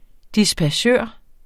Udtale [ dispaˈɕøˀɐ̯ ]